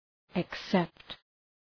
Προφορά
{ık’sept}